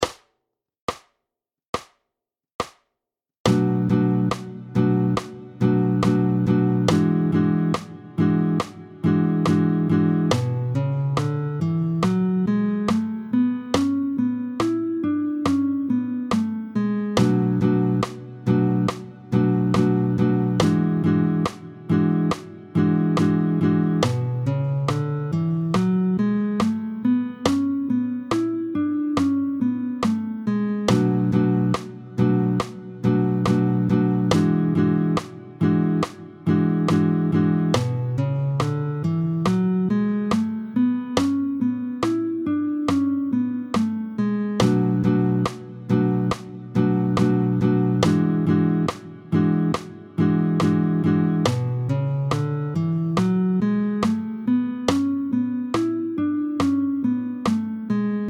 tempo 70